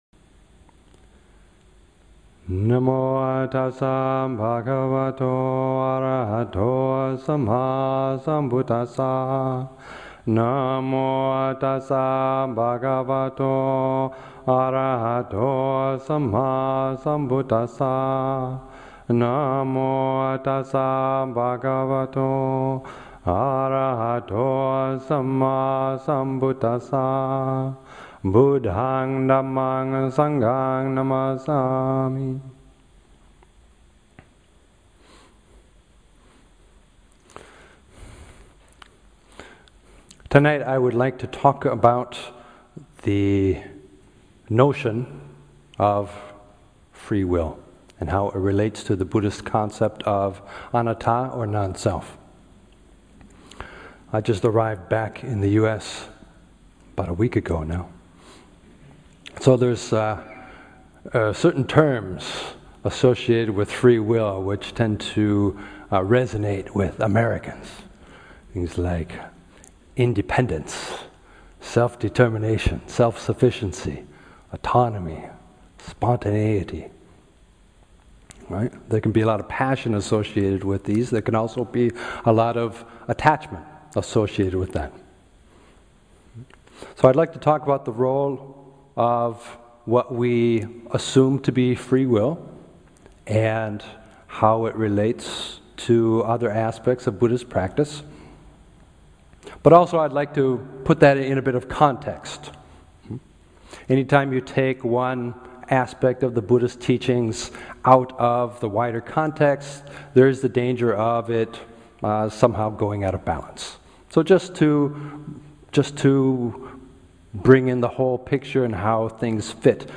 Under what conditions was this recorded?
2017-05-05 Venue: Seattle Insight Meditation Center Series Description (Please note: Due to an equipment limitation, the recording ends before the end of the Q&A period.)